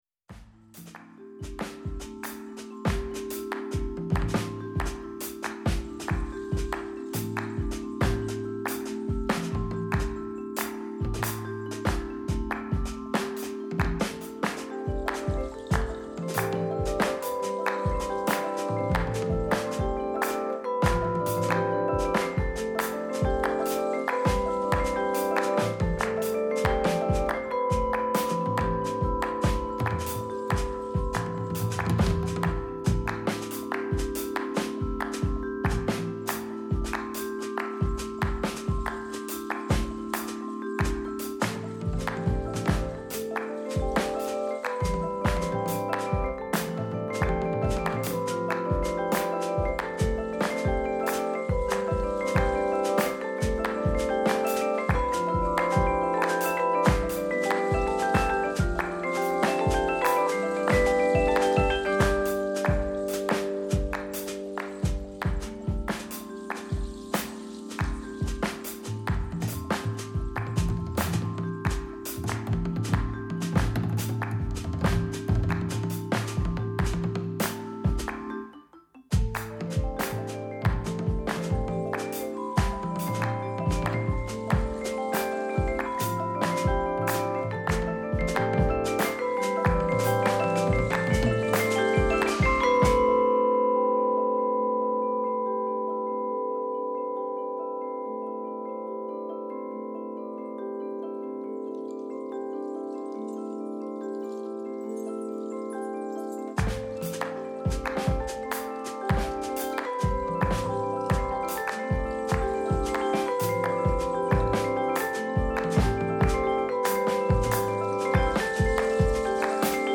Short sections in the last movement glow, settle, then bid adieu, if all too soon.”